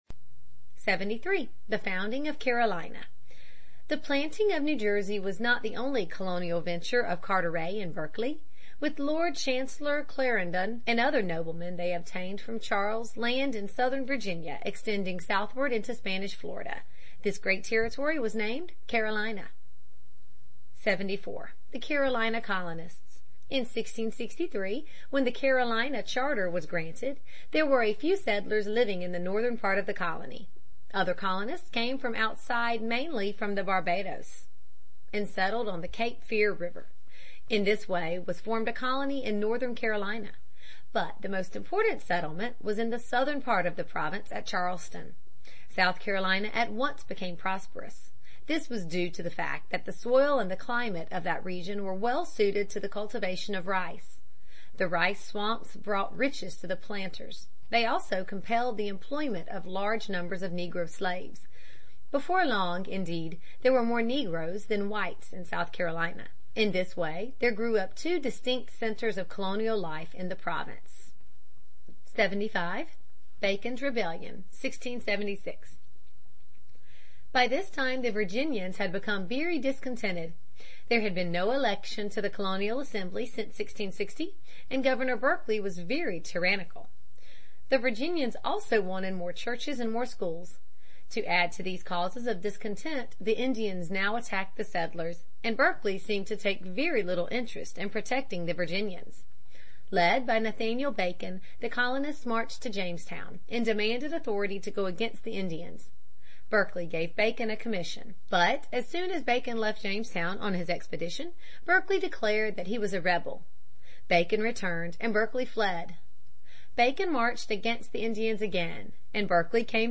在线英语听力室美国学生历史 第24期:查理二世的殖民统治(3)的听力文件下载,这套书是一本很好的英语读本，采用双语形式，配合英文朗读，对提升英语水平一定更有帮助。